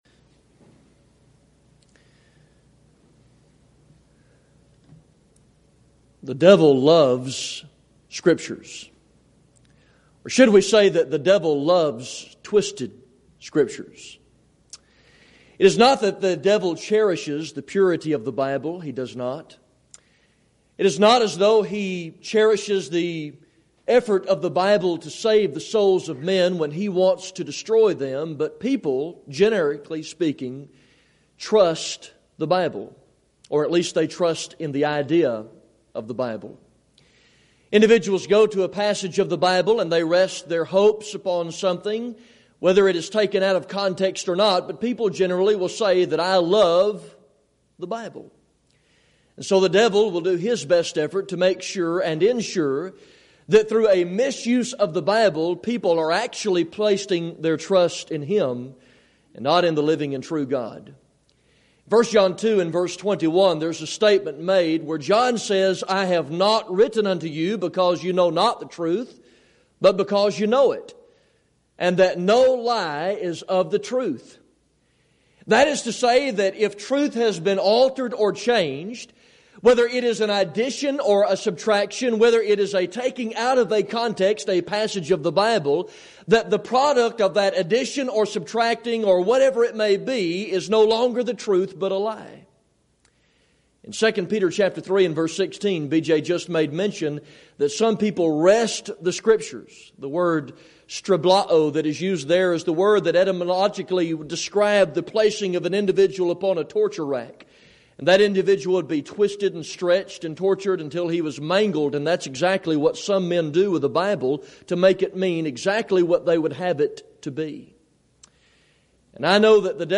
Event: 2nd Annual Schertz Lectures
lecture